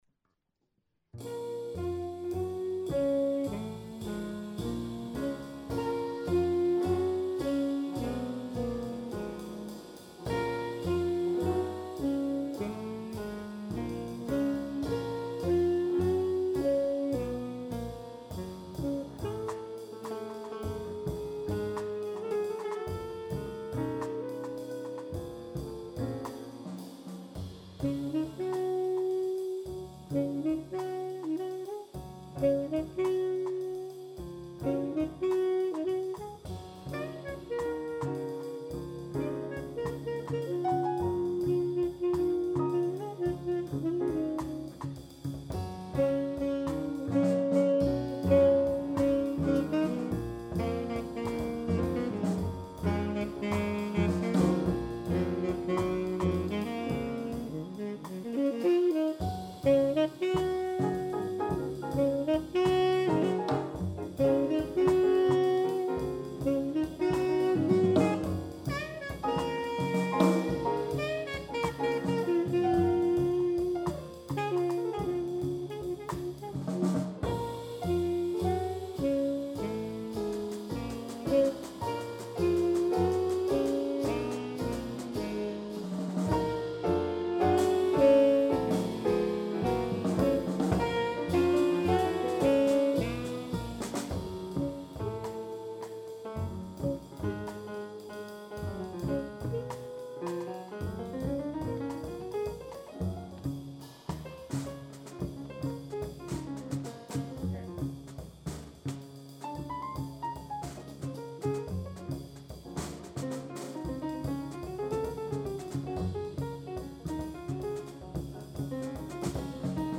from a show we did in MN last year